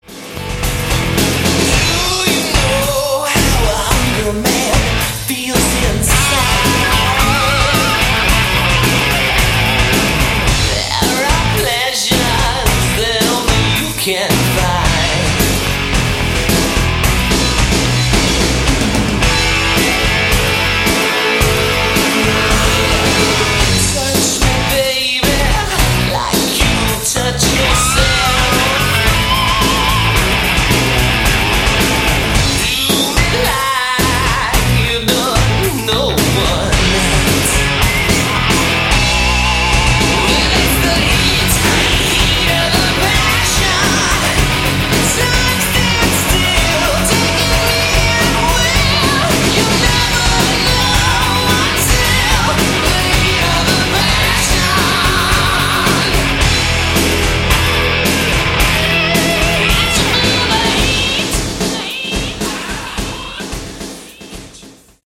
Category: Hard Rock
lead vocals
keyboards, vocals
bass, vocals
drums